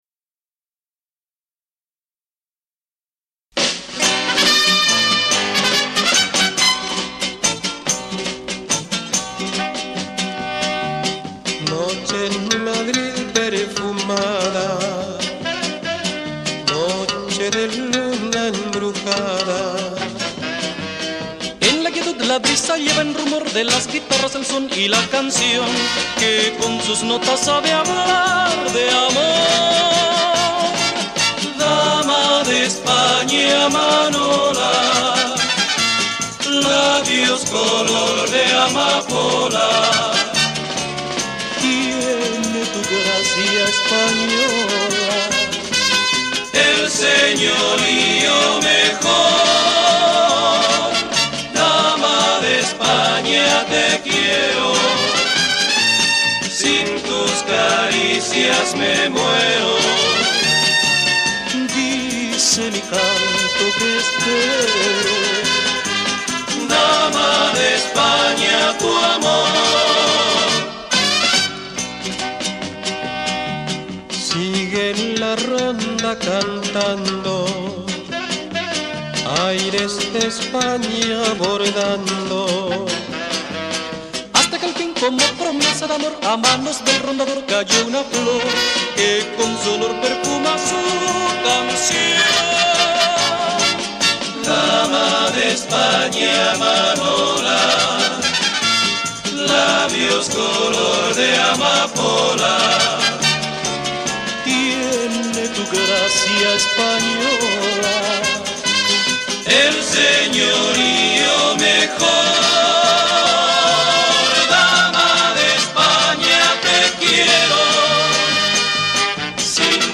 Recording Studio Madeleine, Brussels